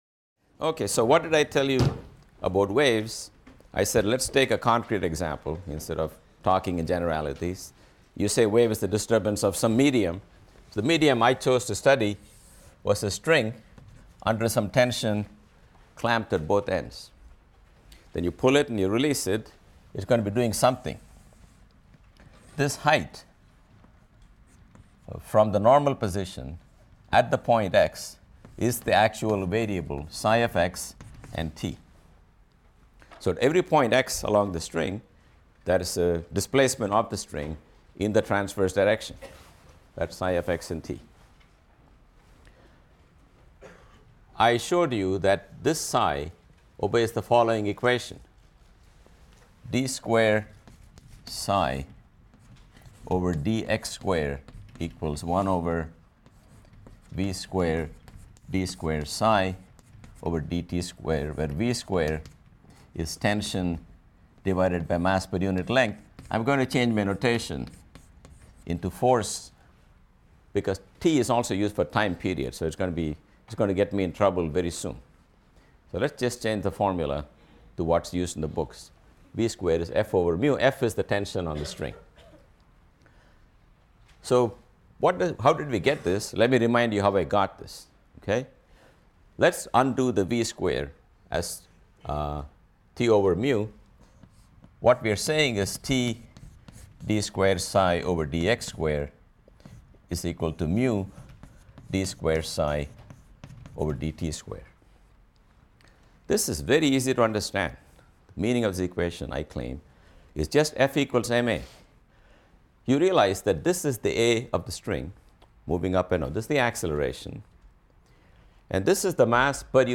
PHYS 200 - Lecture 19 - Waves | Open Yale Courses